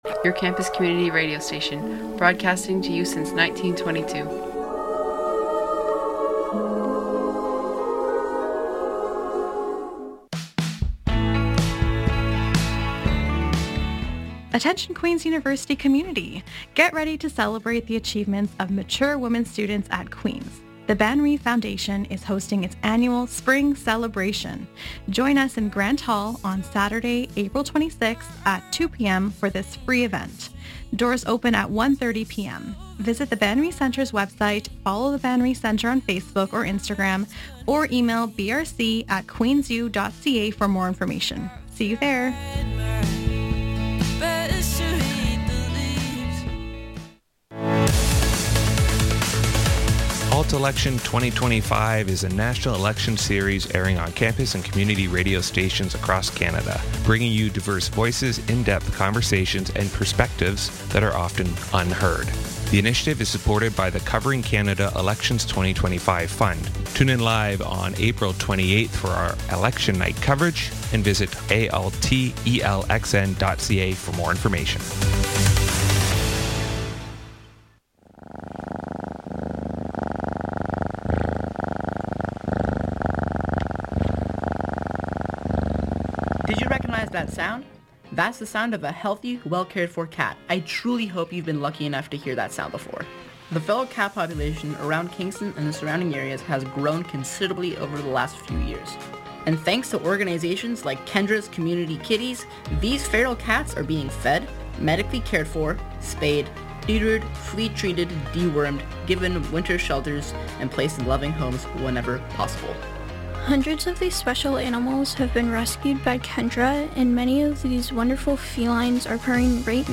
An eclectic mix of Canadian-made music!